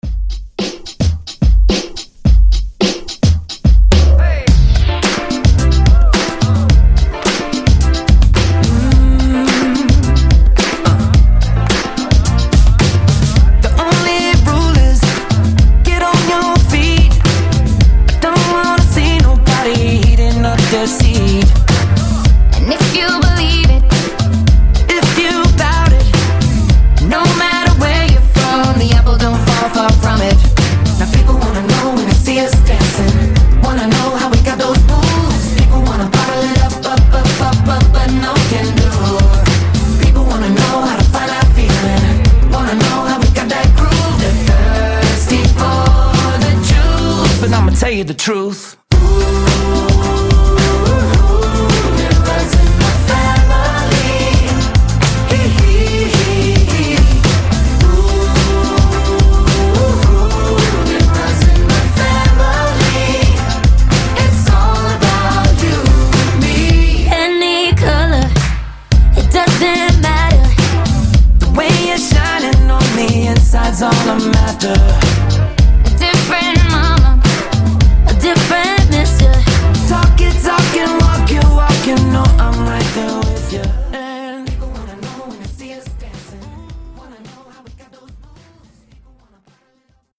Genre: DEEP HOUSE
Clean BPM: 121 Time